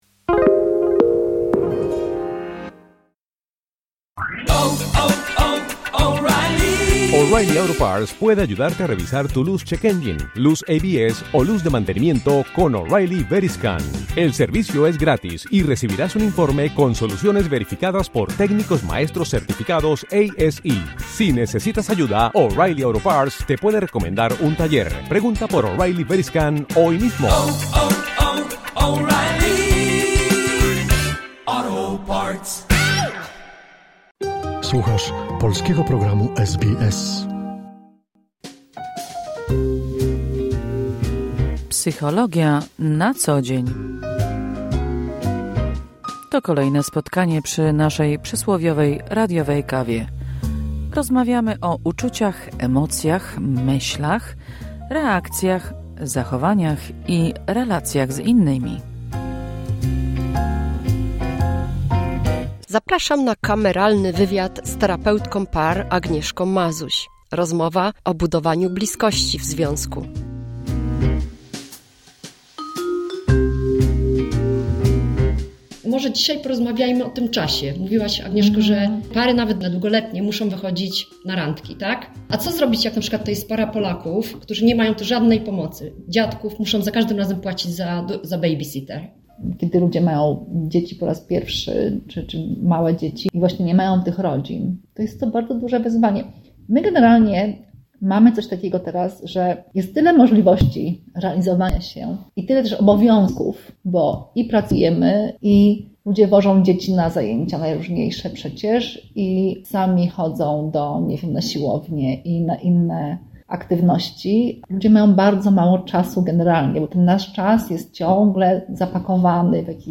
Kolejne spotkanie przy przysłowiowej radiowej kawie z cyklu „Psychologia na co dzień”.